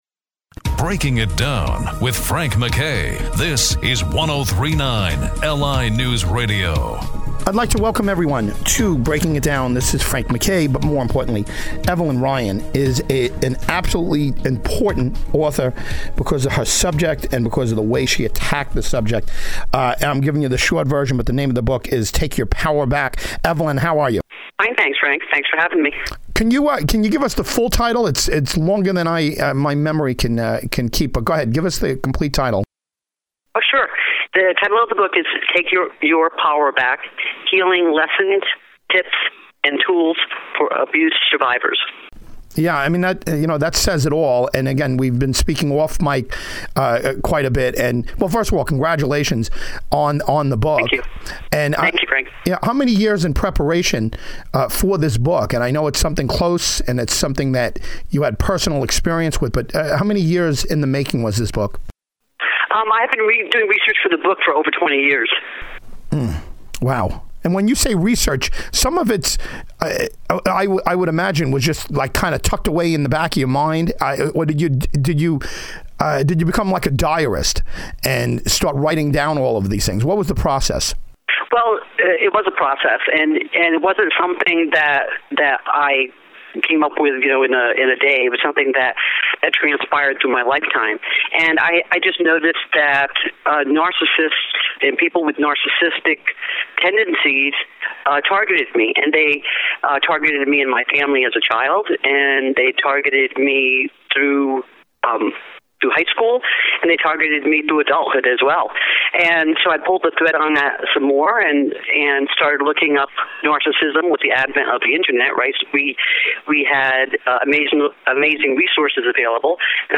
Listen to this mesmerizing discussion on narcissism